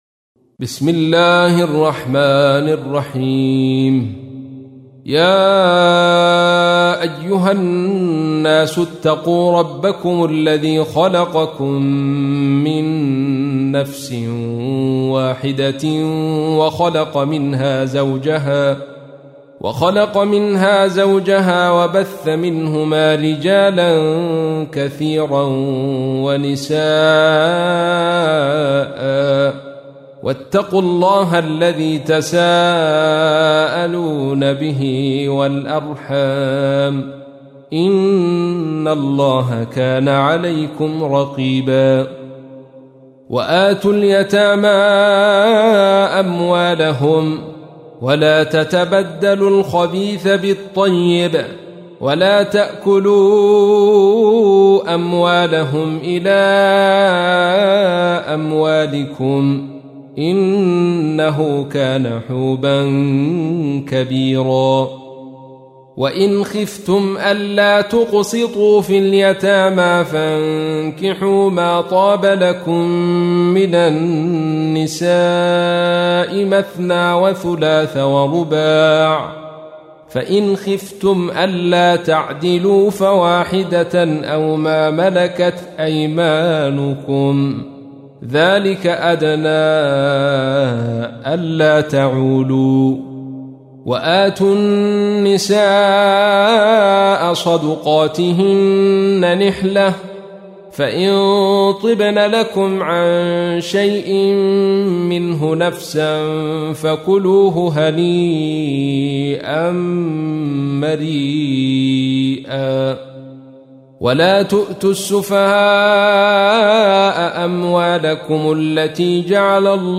تحميل : 4. سورة النساء / القارئ عبد الرشيد صوفي / القرآن الكريم / موقع يا حسين